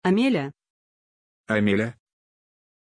Aussprache von Amélya
pronunciation-amélya-ru.mp3